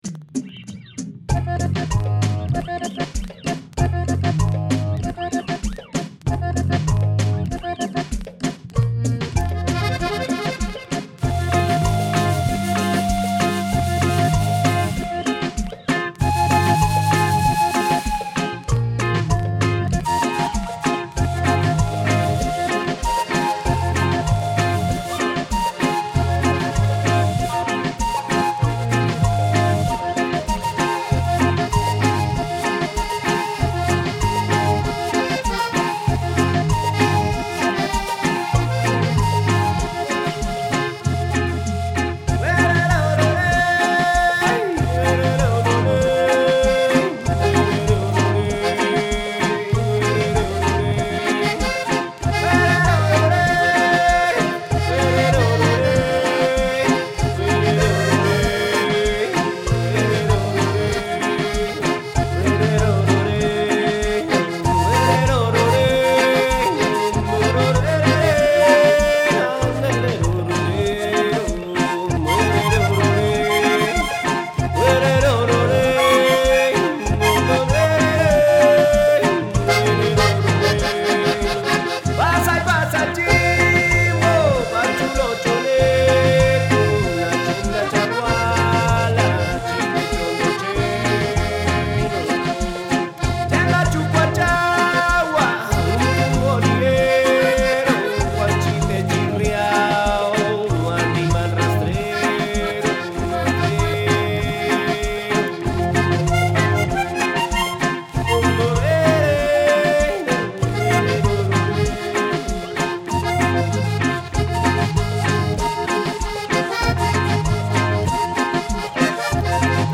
Paisaje Sonoro, Identidad Cultural, Patrimonio Ancestral, Conciencia y Recursos